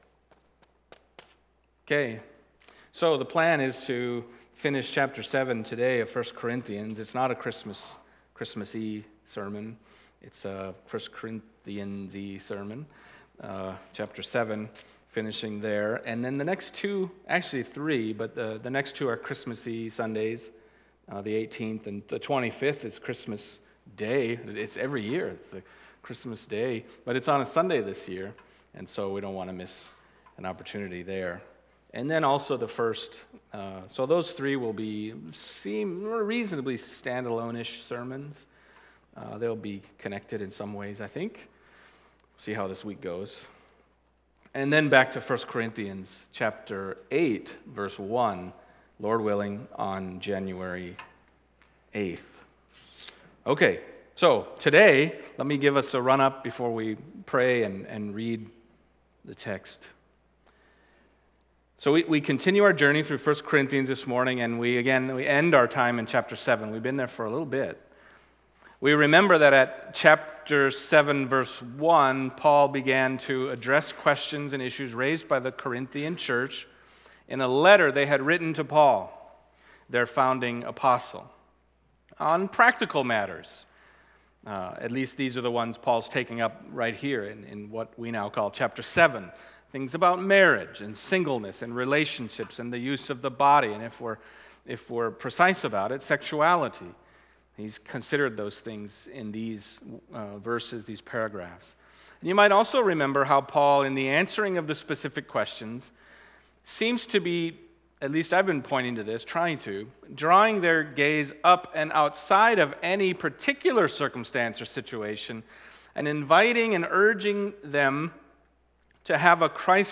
Passage: 1 Corinthians 7:32-40 Service Type: Sunday Morning